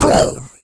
sound2 / sound / monster2 / evil_eye / attack_1.wav
attack_1.wav